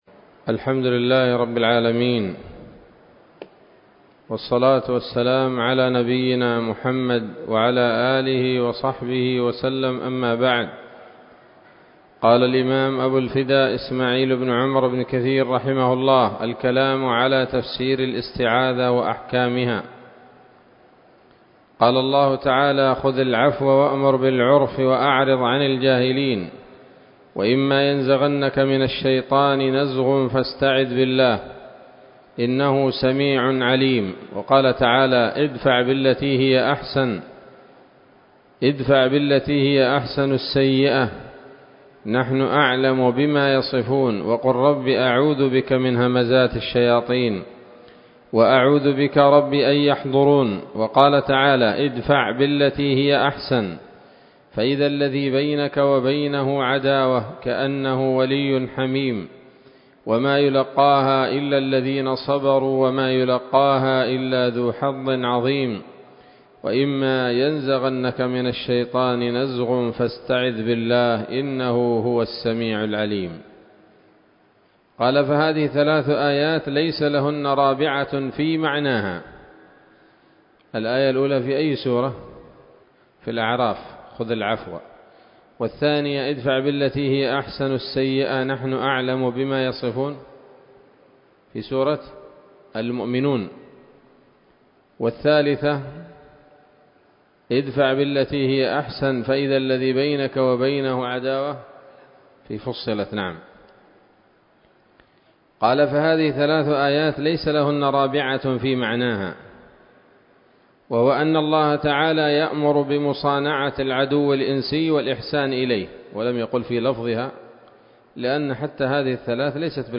الدرس السادس من سورة الفاتحة من تفسير ابن كثير رحمه الله تعالى